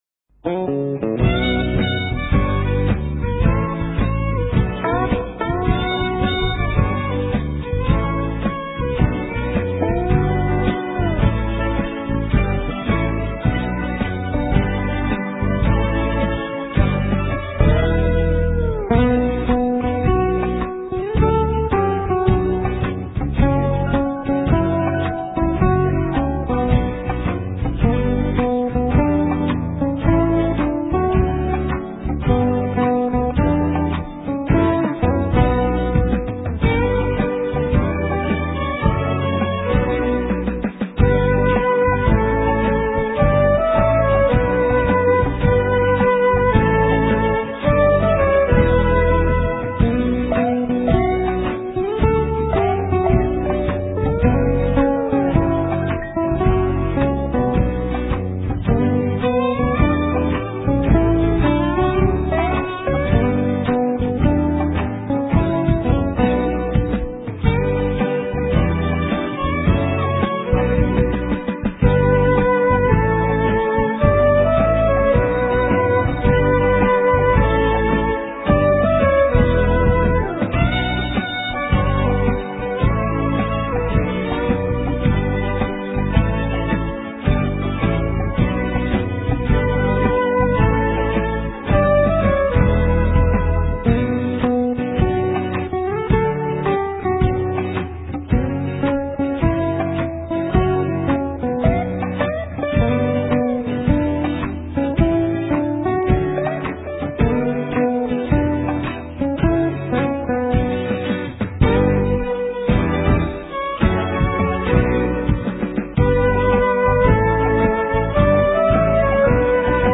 * Ca sĩ: Không lời